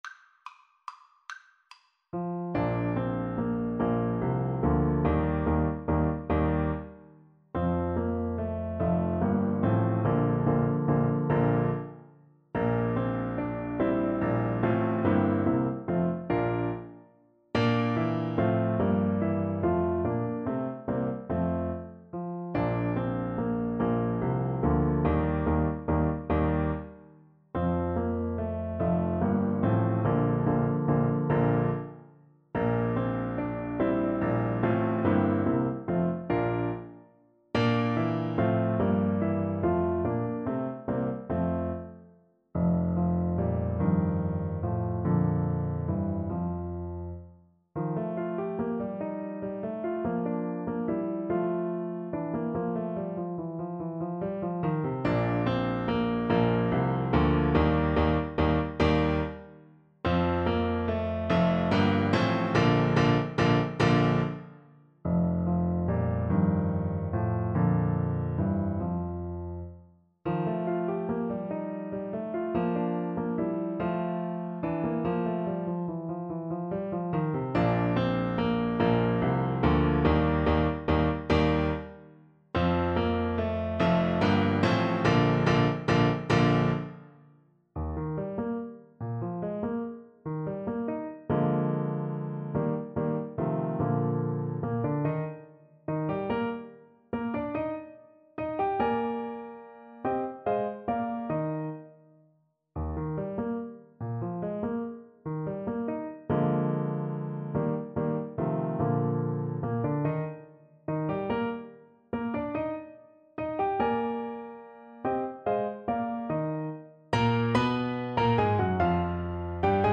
• Piano
Bb major (Sounding Pitch) C major (Clarinet in Bb) (View more Bb major Music for Clarinet )
Moderato =c.144
3/4 (View more 3/4 Music)
Classical (View more Classical Clarinet Music)